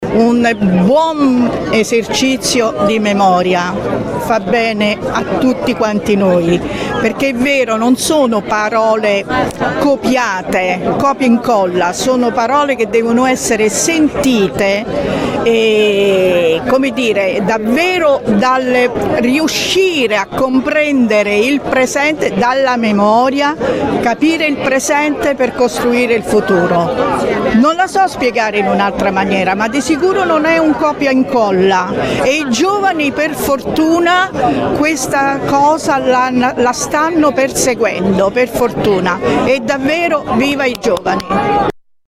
Si sono tenute questa mattina, presso il Monumento ai Caduti di Borgo Sabotino, a Latina, le celebrazioni per il 25 Aprile, Festa della Liberazione d’Italia di cui quest’anno ricorre l’81esimo anniversario.
Ha preso poi la parola per il suo sentito intervento